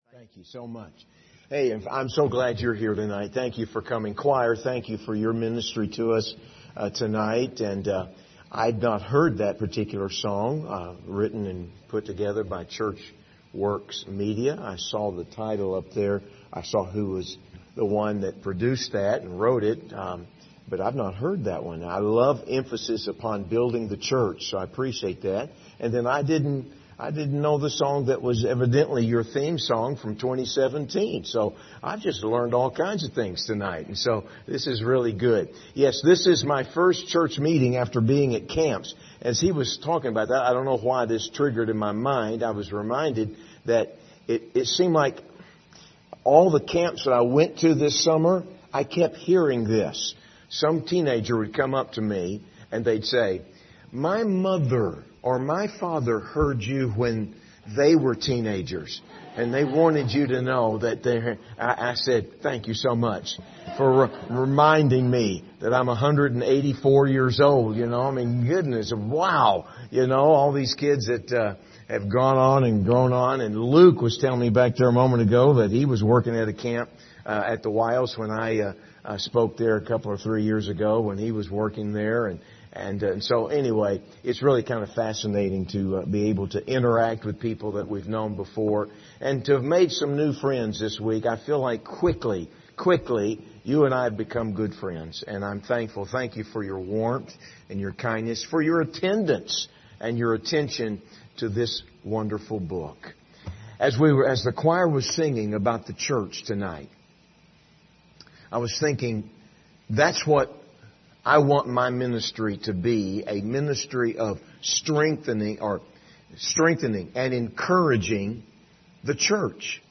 Ephesians 4:29-5:21 Service Type: Revival Service View the video on Facebook « Do You Really Love Me?